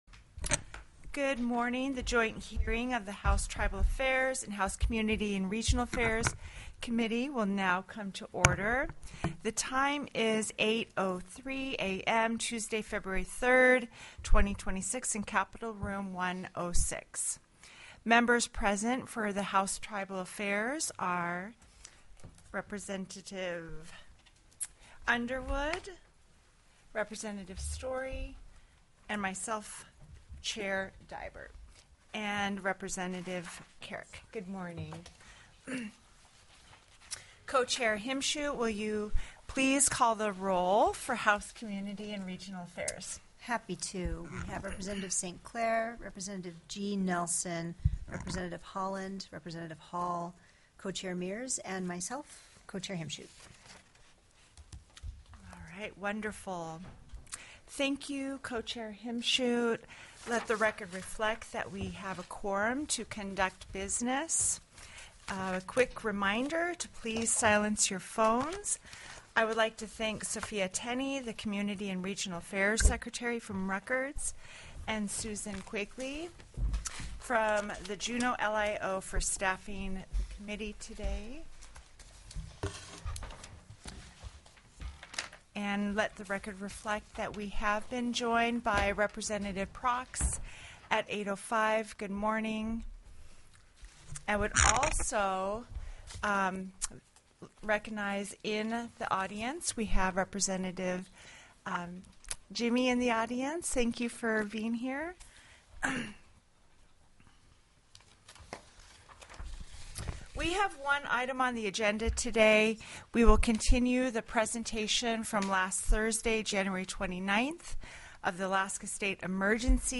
The audio recordings are captured by our records offices as the official record of the meeting and will have more accurate timestamps.
Presentation: Alaska State Emergency Operations TELECONFERENCED Center Update by Bryan Fisher, Director, Division of Homeland Security & Emergency Management